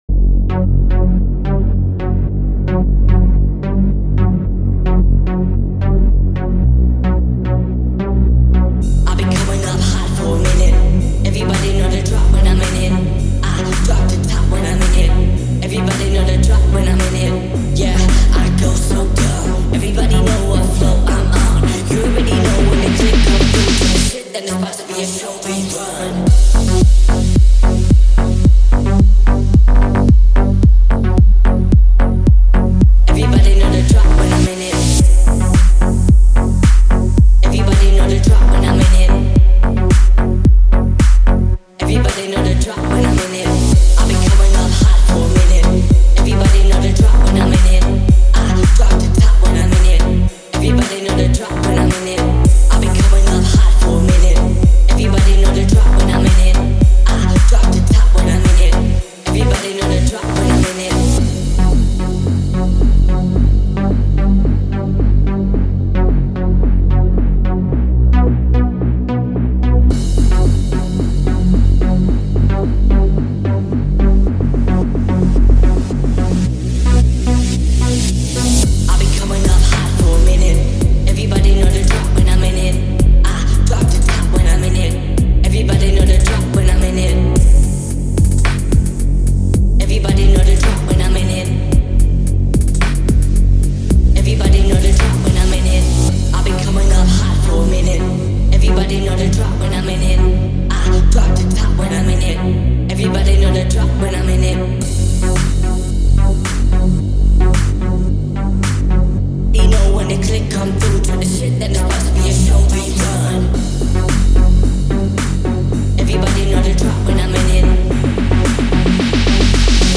динамичная и энергичная песня в жанре поп и R&B